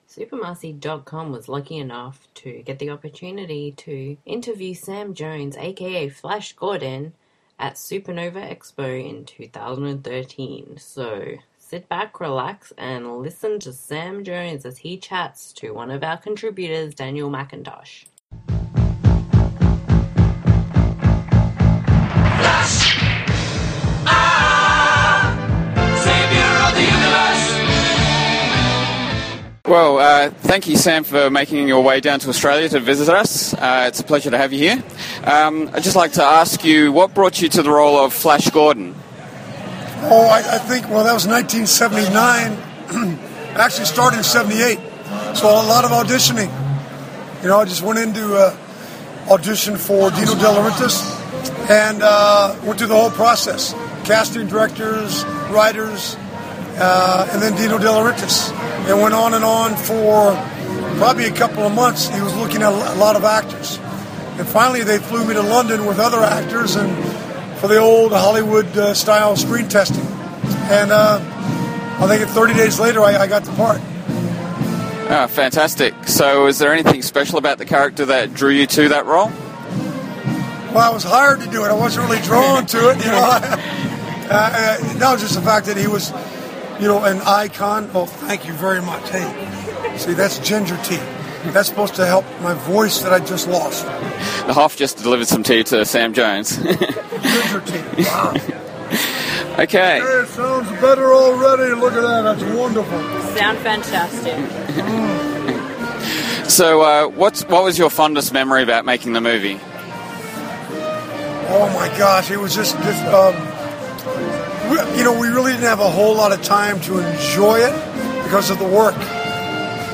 supanova-expo-2013-interview-sam-j-jones-flash-gordon.mp3